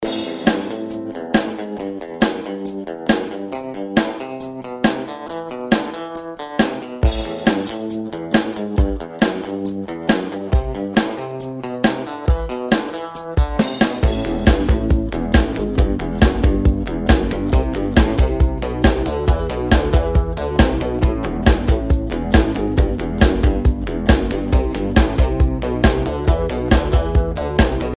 ретро , рок
без слов , инструментальные